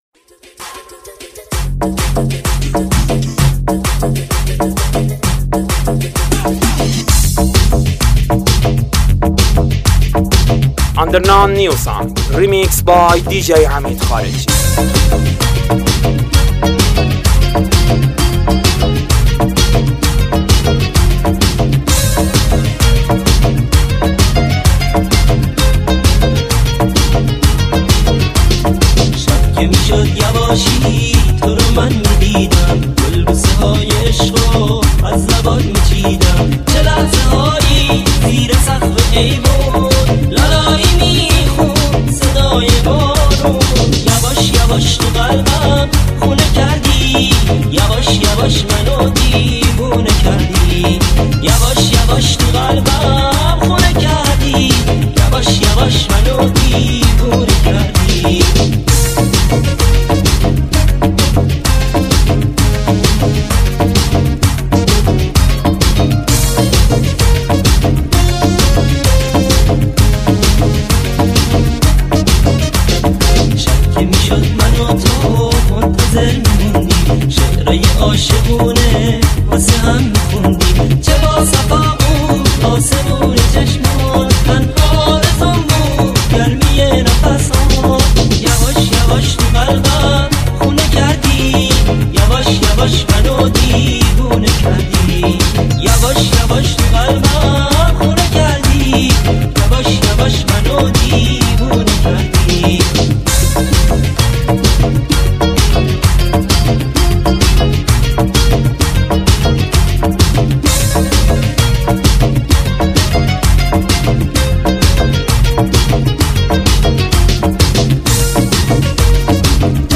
ریمیکس 1